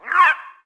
Faerie Dragon Yells
精灵龙叫声